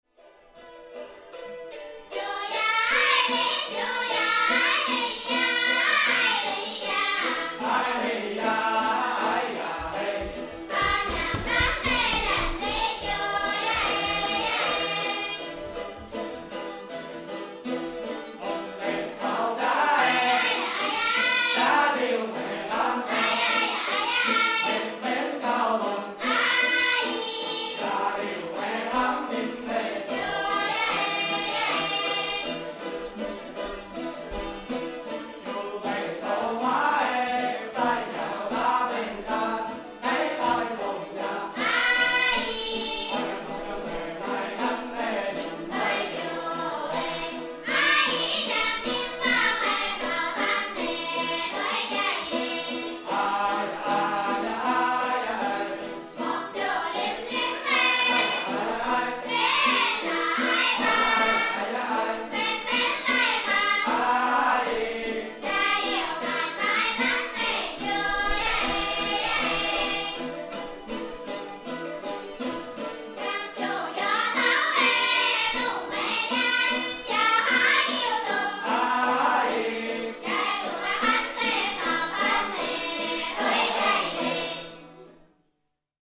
Collectes et résidences de création en Chine.
Leur Grand Chant des Dong – chant polyphonique – est inscrit au patrimoine culturel immatériel de l’UNESCO.
Chant-Dimen-HF-2014.mp3